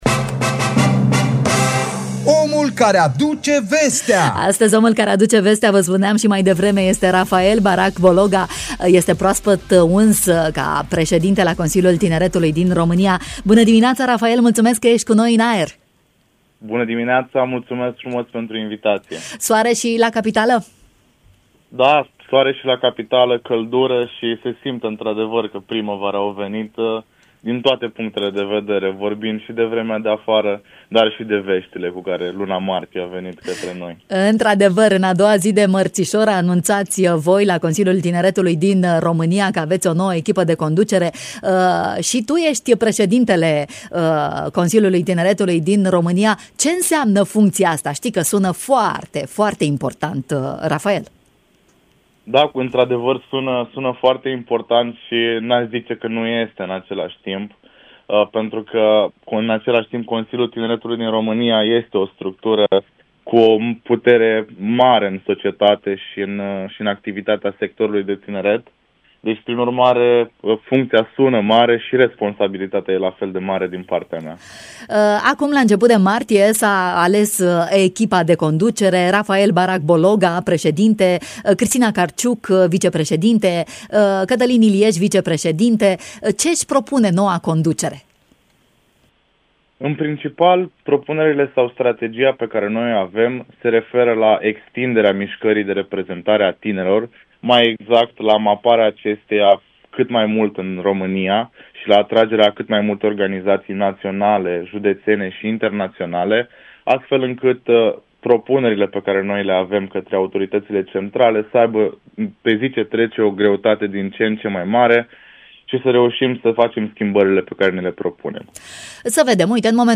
Care sunt problemele tinerilor de la noi și ce soluții pot fi implementate anul acesta am aflat în matinalul Radio România Iași